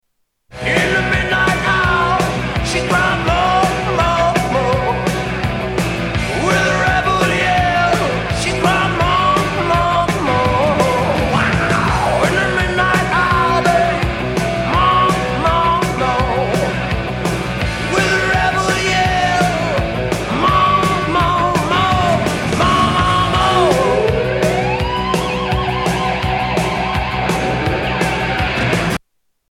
Tags: Sound Effects Rock Truetone Ringtones Music Rock Songs